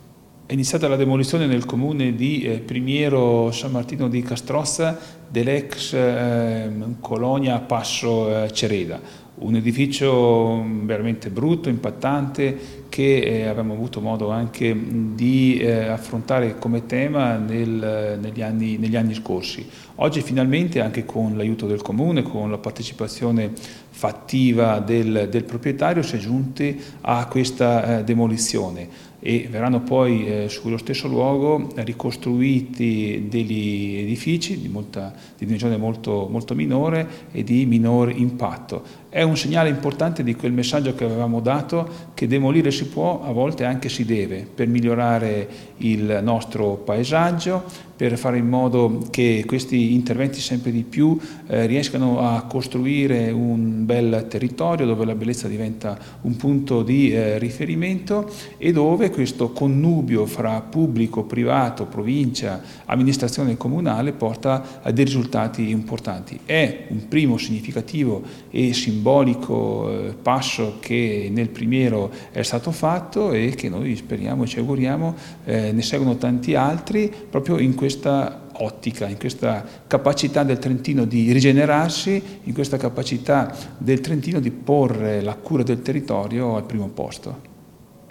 Dichiarazione audio assessore Carlo Daldoss
Intervista assessore Daldoss: